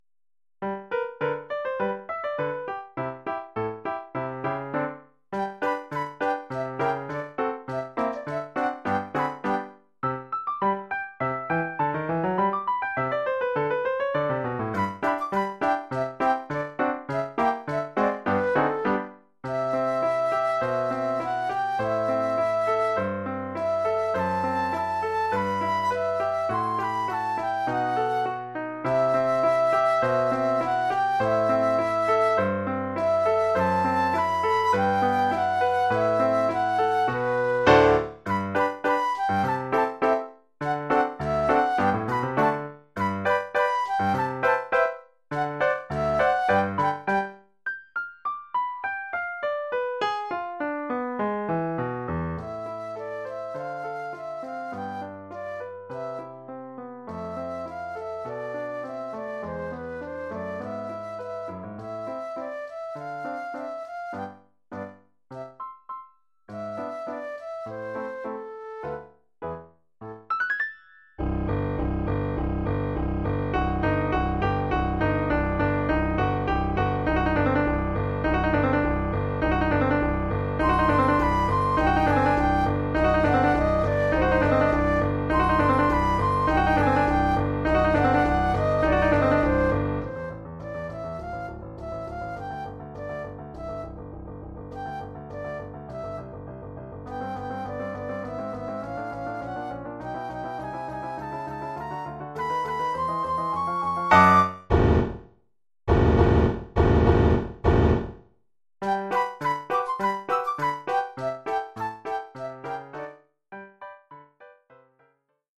Oeuvre pour flûte avec accompagnement de piano.